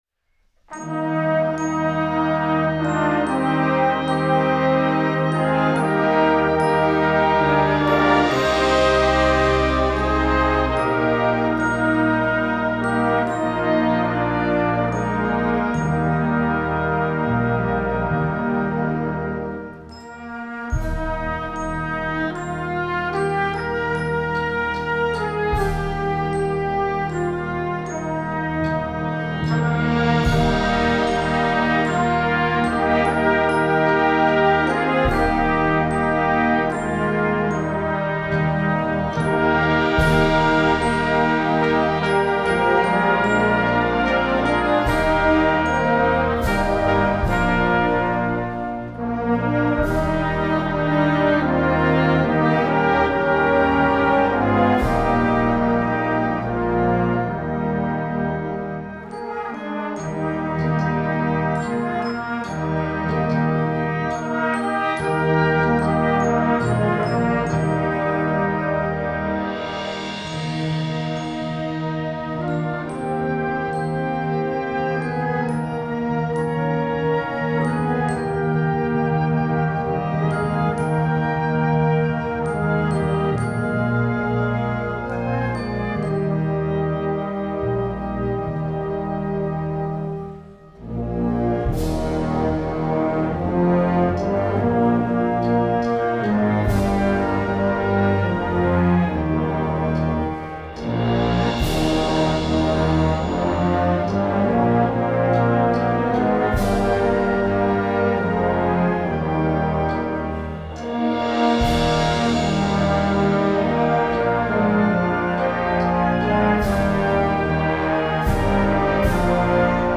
Gattung: Weihnachtsmusik für Blasorchester
Besetzung: Blasorchester